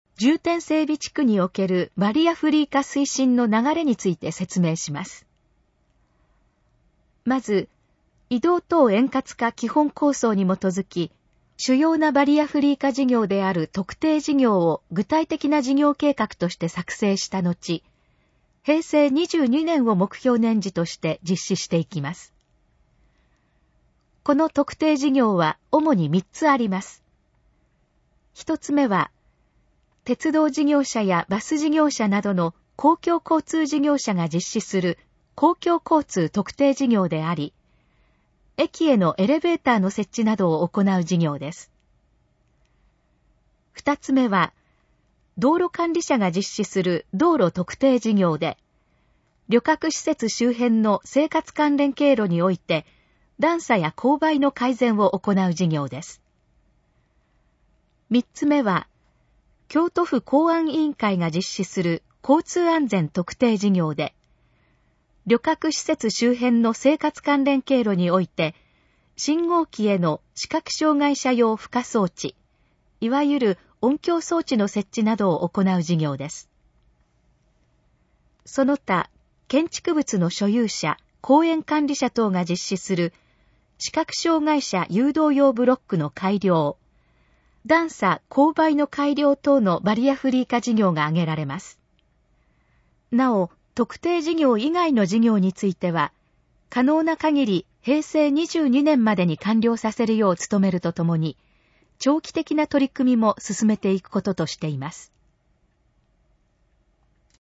このページの要約を音声で読み上げます。
ナレーション再生 約420KB